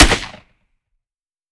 12ga Pump Shotgun - Gunshot A 002.wav